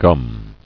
[gum]